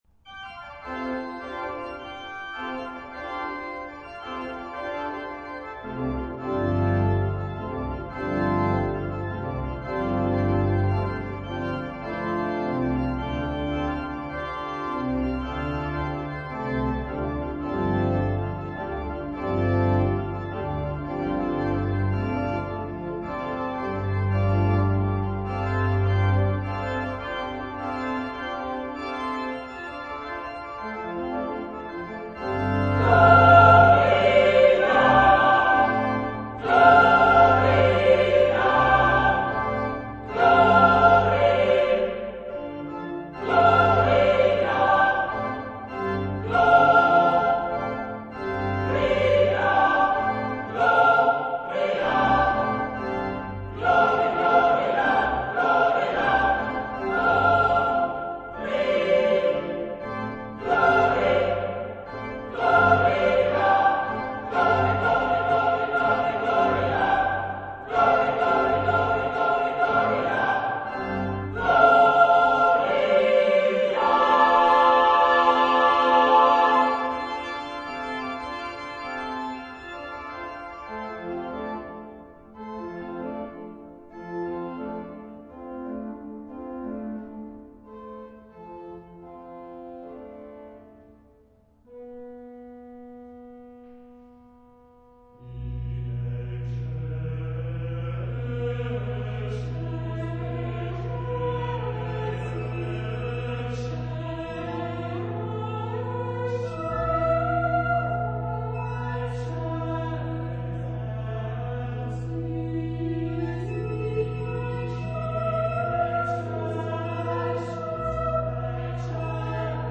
Sacré.